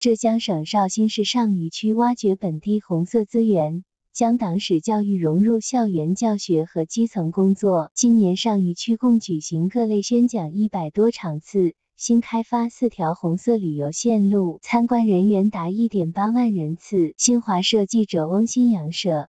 之前果然是训练时间太短了，我接着训练下去，现在到3700epoch了，效果提升了很多。 不过比wavernn还是差很多，语调怪怪的在飘一样，也有回音的感觉。